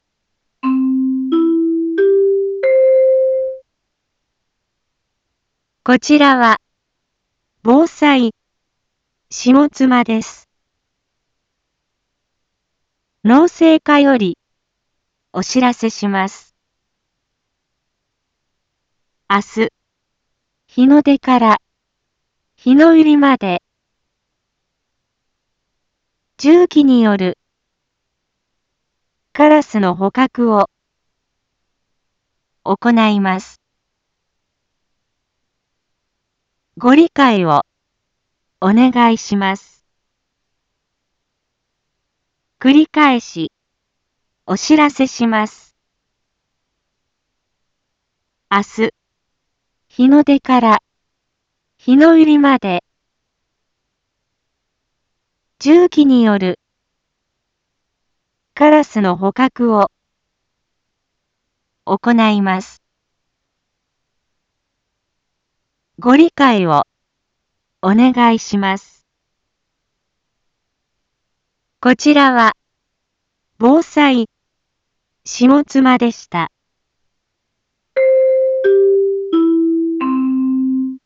一般放送情報
Back Home 一般放送情報 音声放送 再生 一般放送情報 登録日時：2022-01-08 18:01:22 タイトル：有害鳥獣捕獲についてのお知らせ インフォメーション：こちらは、防災下妻です。